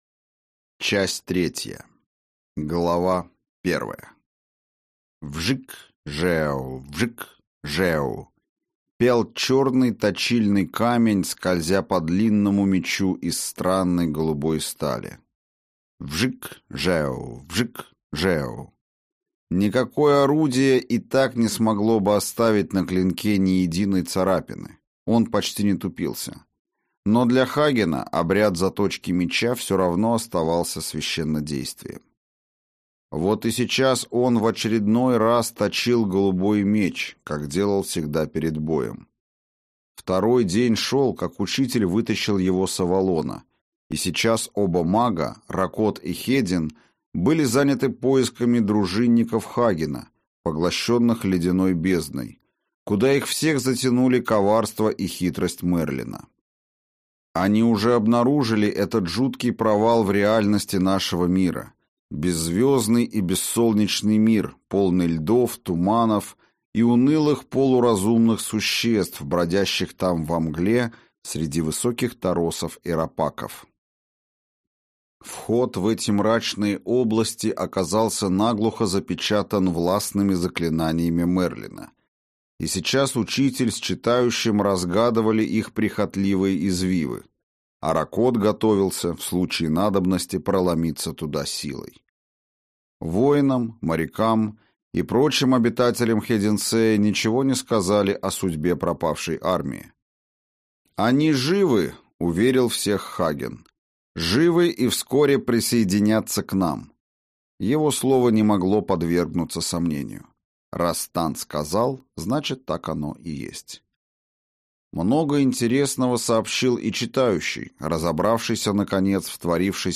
Аудиокнига Гибель богов (Книга Хагена). Часть 3 | Библиотека аудиокниг